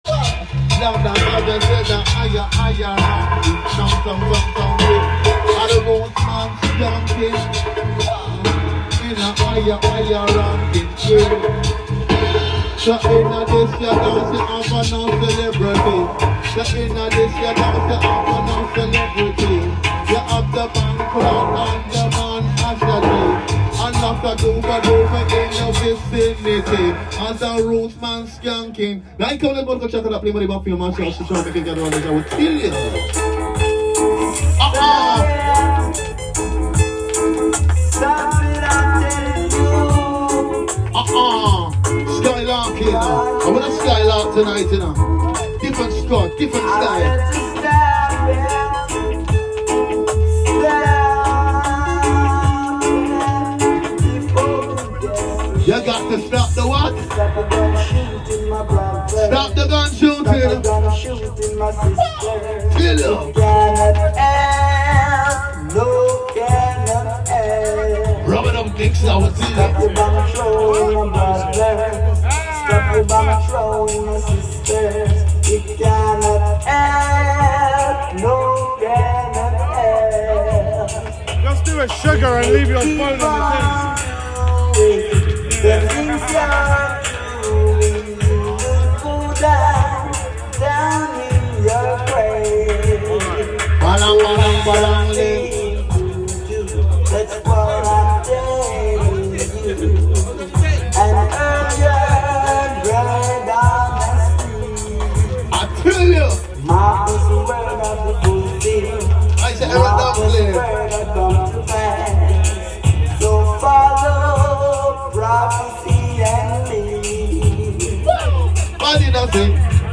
known predominantly as a roots and culture sound but expect to hear a wide range of Jamaican revival sounds on vinyl and exclusive dubplate
As usual Ram Jam residents will be on hand to warm up the party in fine style and close off the party in a usual 1 for 1.